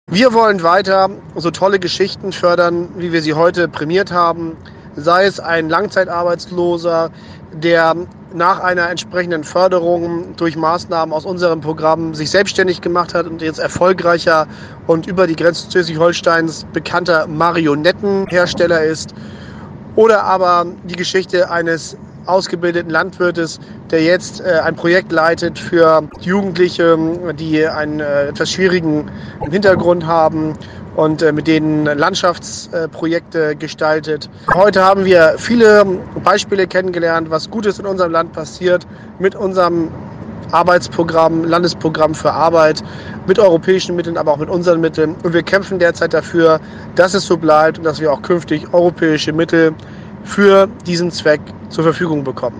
ESF-Preisübergabe Wirtschaftsakademie
Rohlfs sagte nach der Preisverleihung weiter:
rohlfs_esf-gesichter.mp3